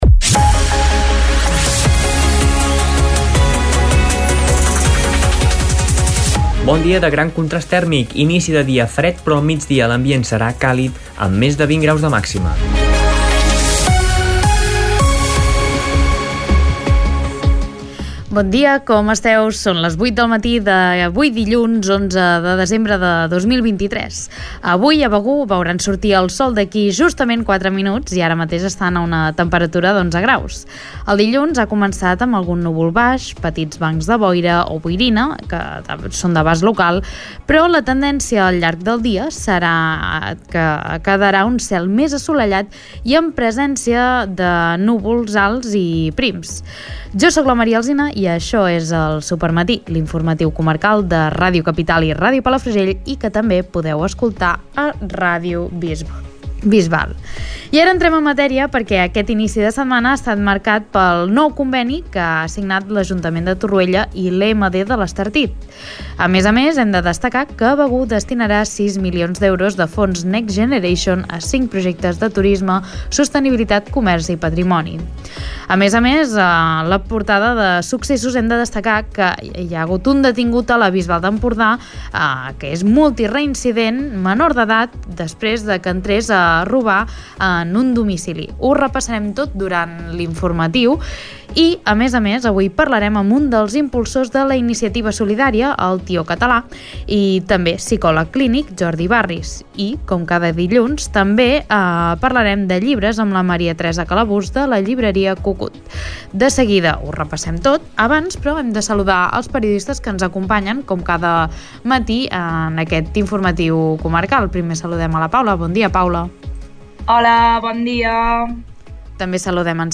Escolta l'informatiu d'aquest dilluns